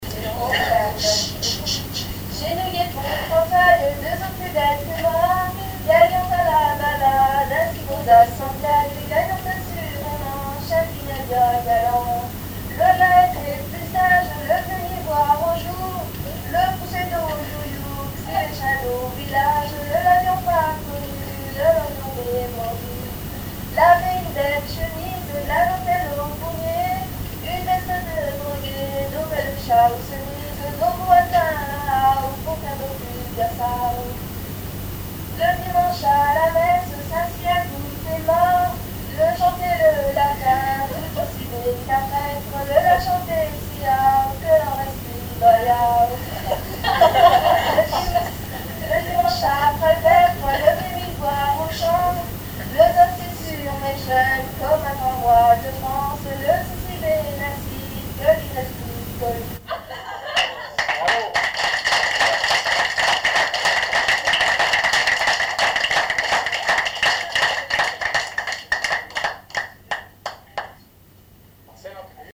Genre strophique
Répertoire d'un bal folk par de jeunes musiciens locaux
Pièce musicale inédite